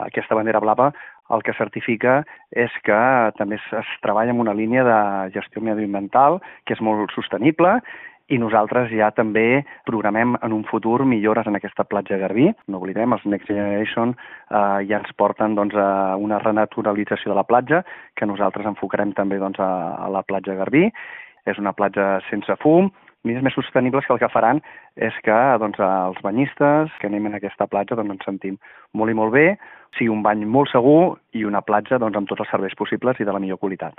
El regidor de Platges de l’Ajuntament de Calella, Manel Vicente, ha declarat que el govern té projectes confirmats i d’altres en estudi per incrementar els nivells de sostenibilitat en la gestió i usos del litoral.